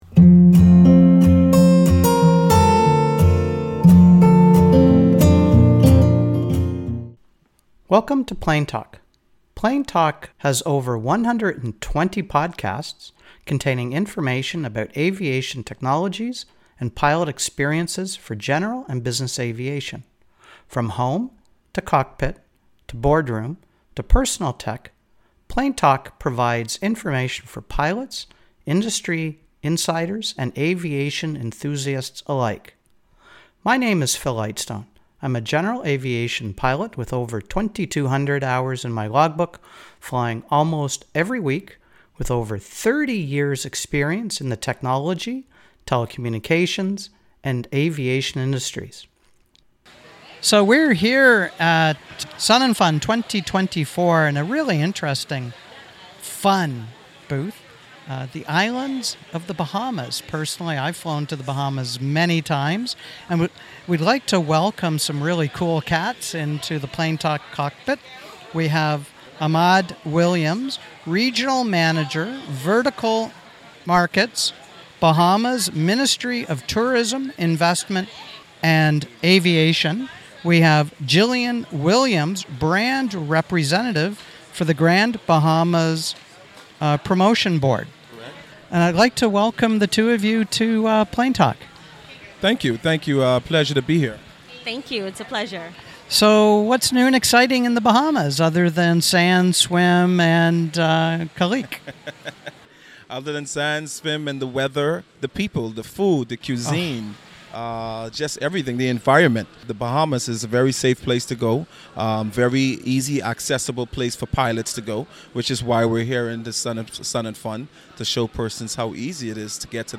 Recorded in the Islands of the Bahamas booth at SUNnFUN 2024, an interesting conversation with three inspiring individuals from the department of Bahamian tourism.